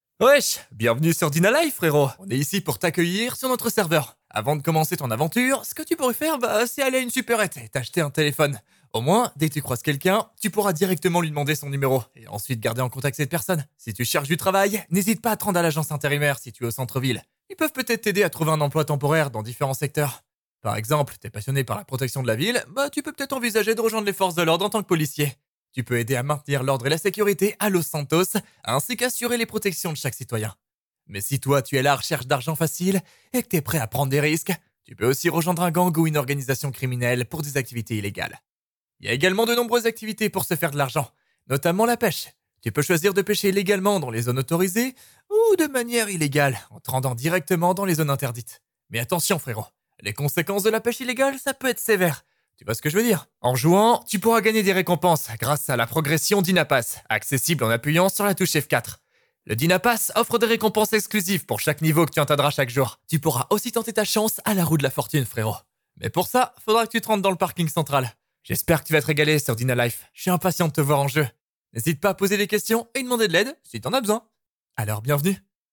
Voix off Dynalife.mp3
voixoff-dynalife.mp3